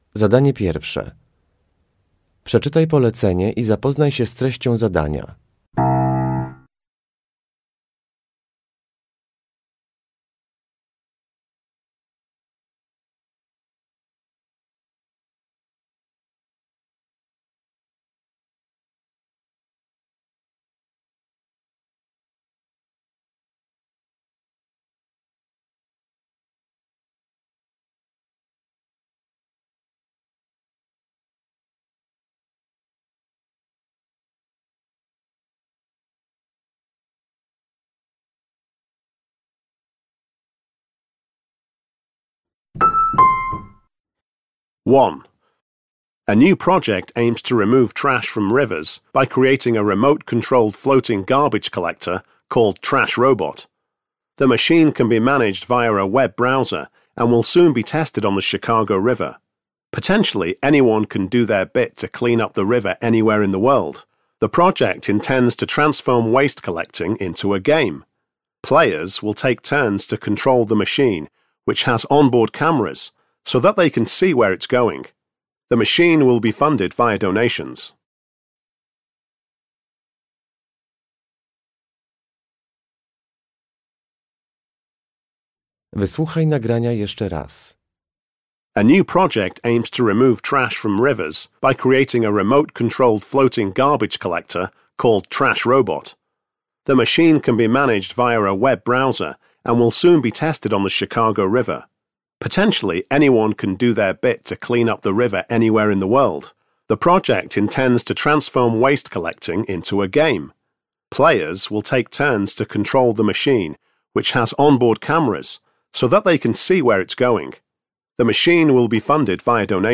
Uruchamiając odtwarzacz z oryginalnym nagraniem CKE usłyszysz dwukrotnie trzy teksty.
1.3. The woman